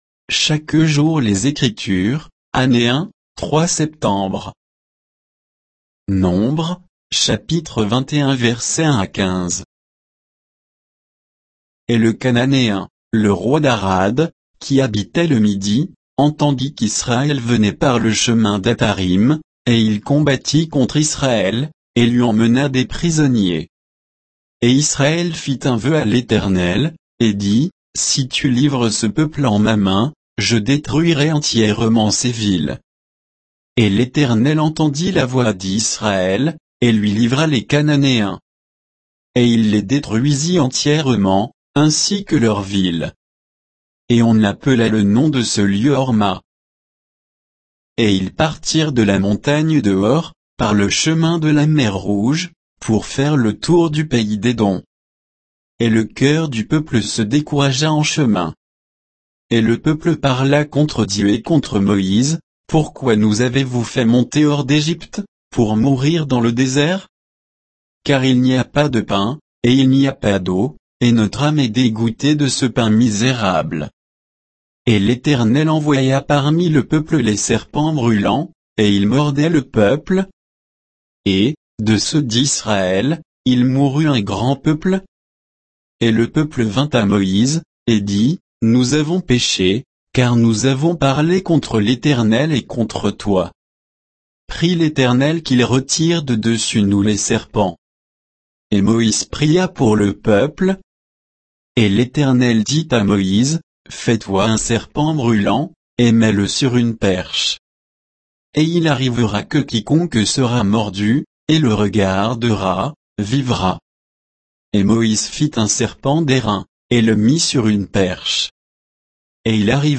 Méditation quoditienne de Chaque jour les Écritures sur Nombres 21